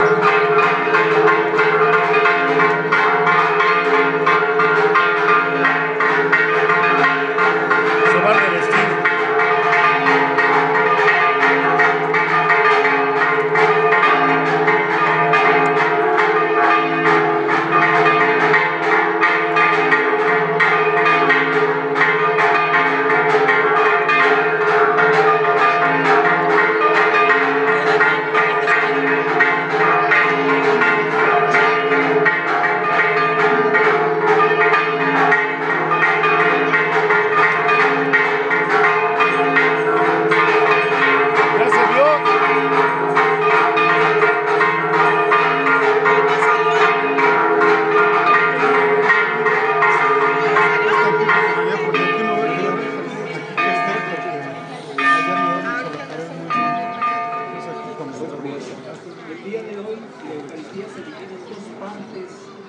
Campanadas de Santa Clara